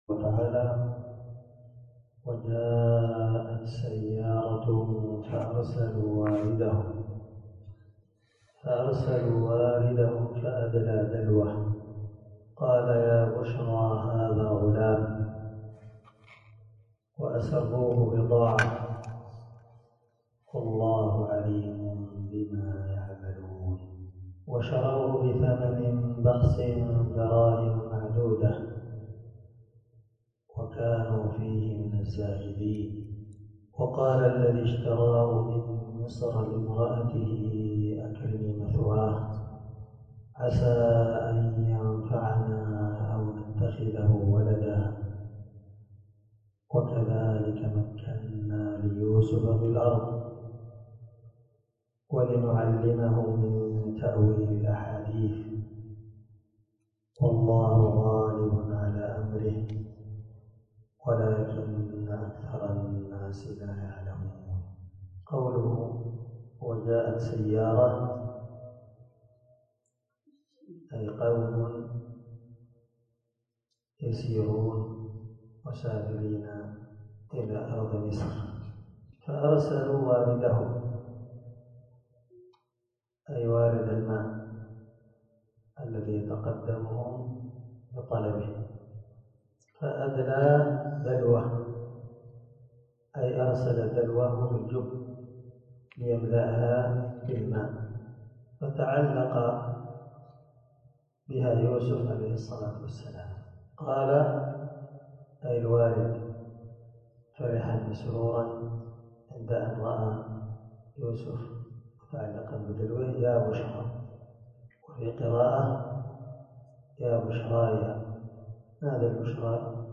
656تفسير السعدي الدرس6 آية (19_21) من سورة يوسف من تفسير القرآن الكريم مع قراءة لتفسير السعدي
دار الحديث- المَحاوِلة- الصبيحة.